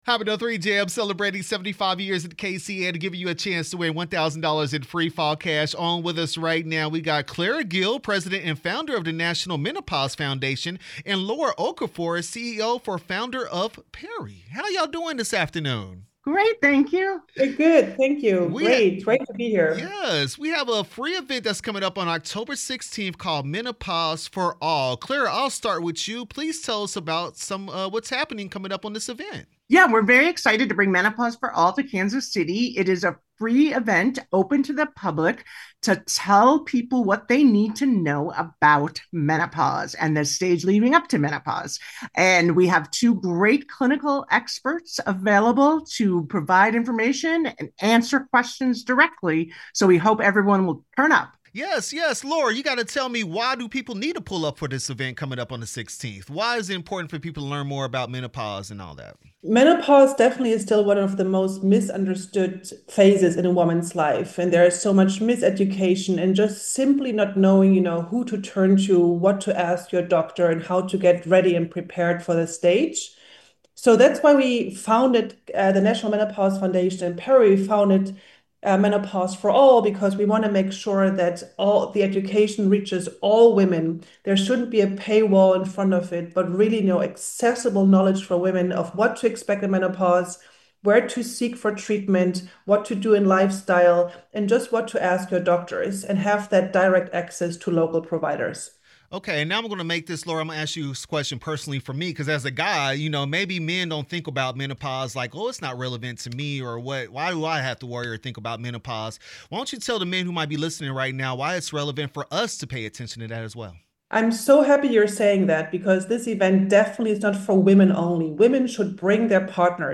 3 Hot Minutes/Menopause For All interview 10/13/25